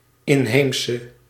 Ääntäminen
UK : IPA : [ˌæb.ə.ˈɹɪdʒ.ə.nəl]